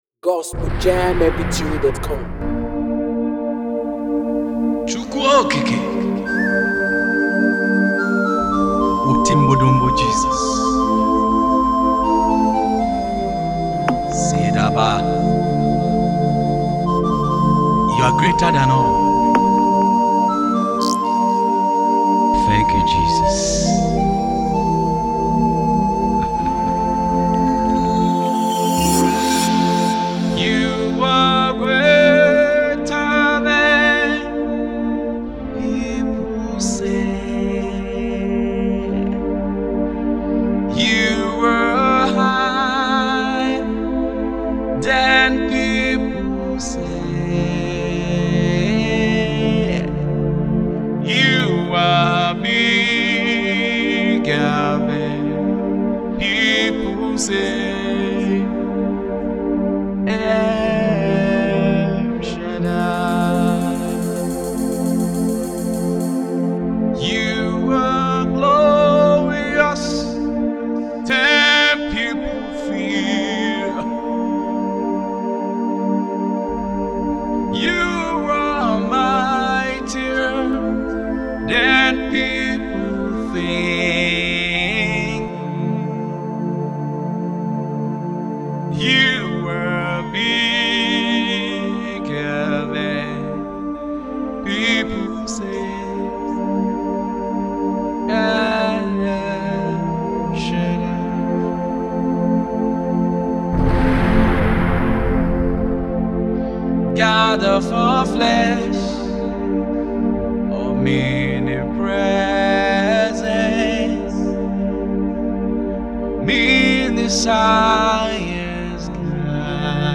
Gospel Minister, Songwriter, and Vocalist
soul-stirring gospel anthem